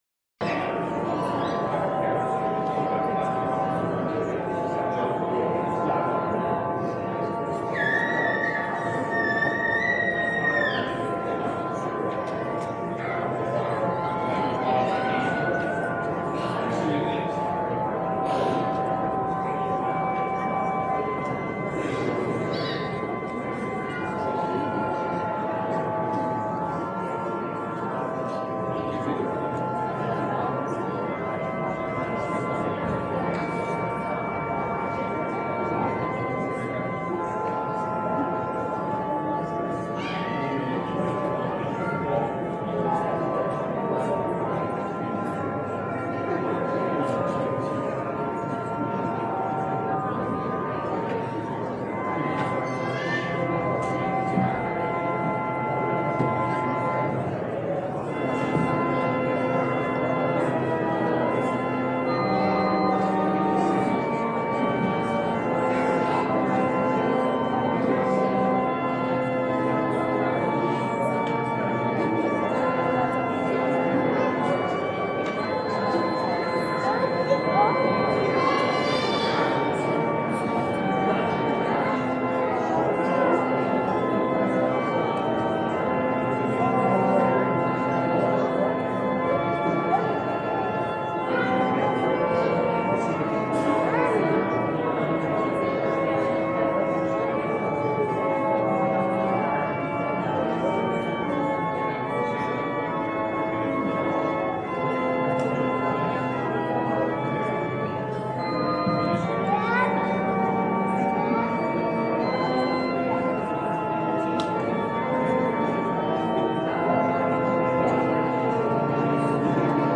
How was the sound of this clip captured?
Series: Children's Christmas Program, Christmas